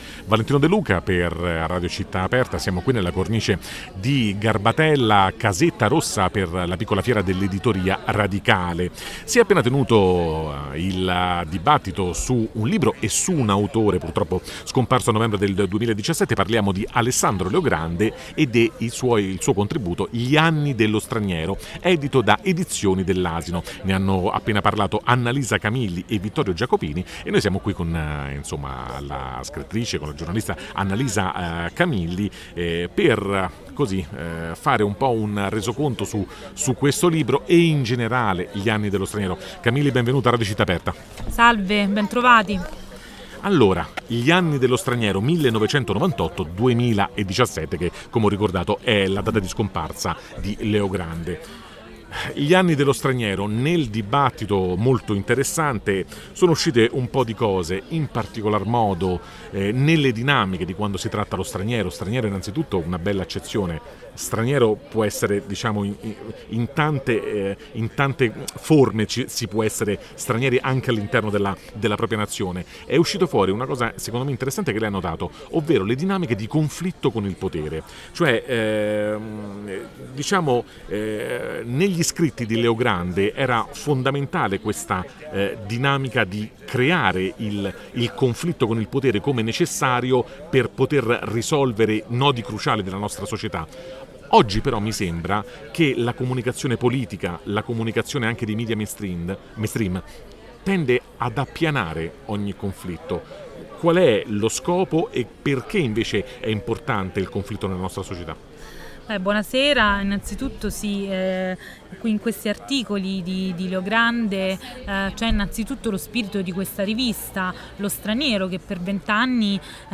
Il 28 – 29 e 30 settembre 2021, nella sede di Casetta Rossa al quartiere Garbatella di Roma, si è tenuta un’importante manifestazione dell’editoria indipendente e “radicale”.